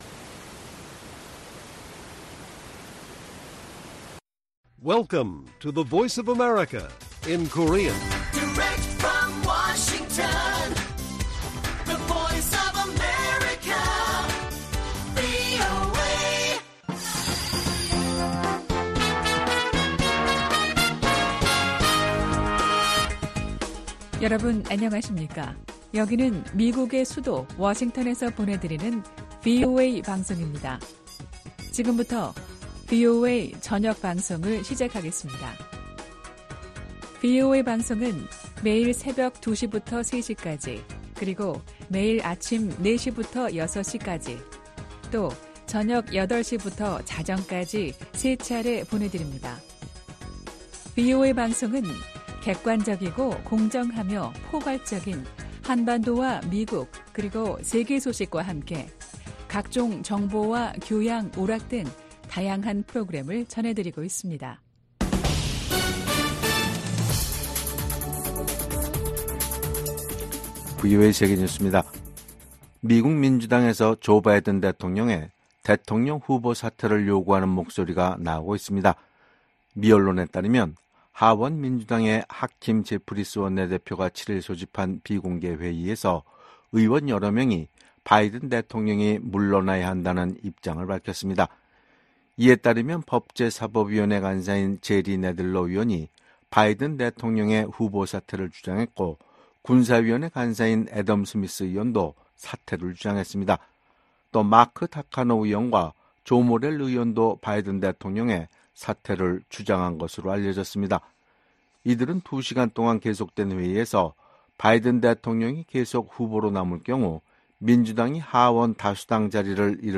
VOA 한국어 간판 뉴스 프로그램 '뉴스 투데이', 2024년 7월 8일 1부 방송입니다. 북한의 핵과 미사일 프로그램이 주변국과 세계안보에 대한 도전이라고 나토 사무총장이 지적했습니다. 윤석열 한국 대통령은 북-러 군사협력이 한반도와 국제사회에 중대한 위협이라며 한-러 관계는 전적으로 러시아에 달려 있다고 말했습니다. 미국 고위관리는 중국의 러시아 지원이 전쟁을 부추기고 있으며, 워싱턴 나토 정상회의에서 이 문제가 중요하게 논의될 것이라고 밝혔습니다.